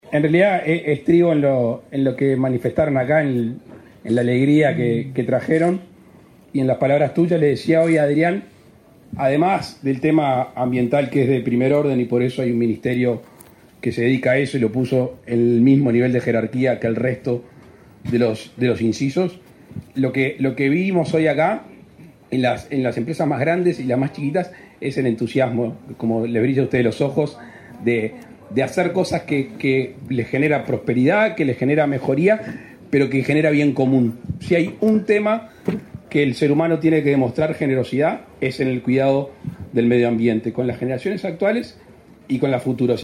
Palabras del presidente de la República, Luis Lacalle Pou | Presidencia
El presidente de la República, Luis Lacalle Pou, entregó el Premio Nacional de Ambiente Uruguay Sostenible, este 12 de junio.